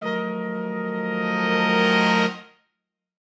Index of /musicradar/gangster-sting-samples/Chord Hits/Horn Swells
GS_HornSwell-F7b2sus4.wav